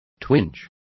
Complete with pronunciation of the translation of twinge.